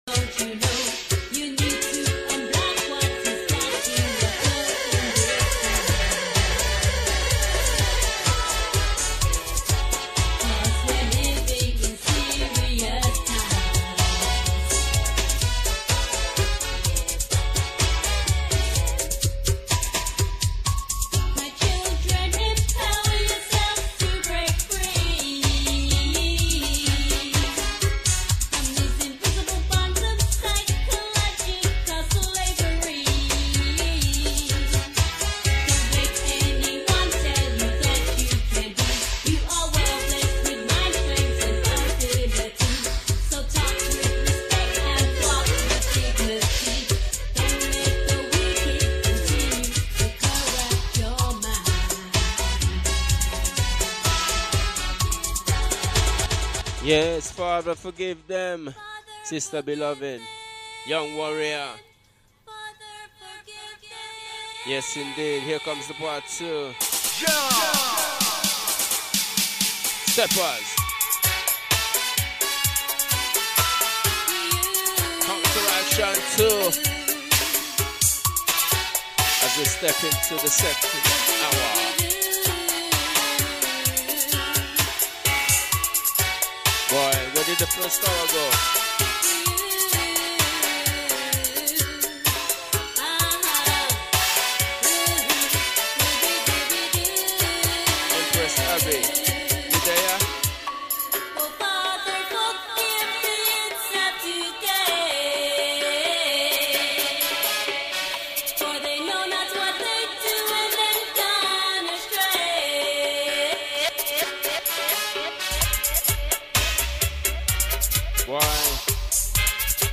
Reggae Roots & Dub Livications
Live & Direct.